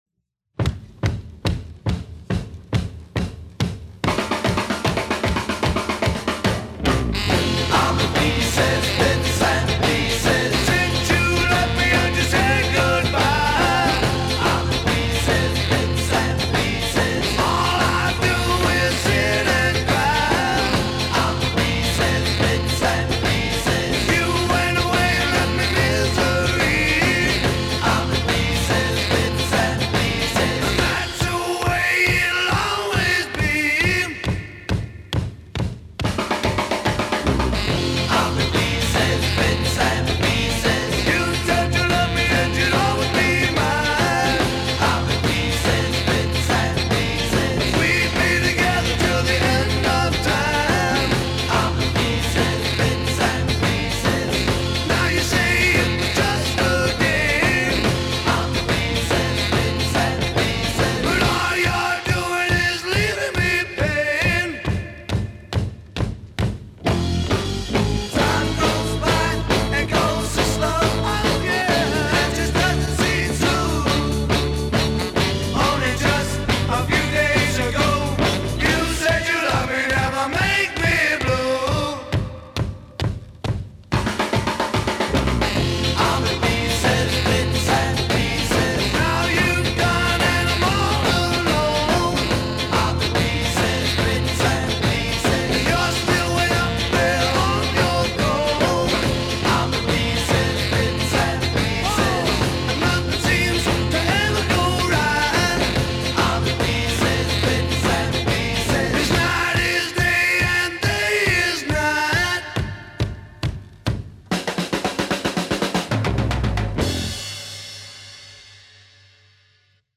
бит
поп-рок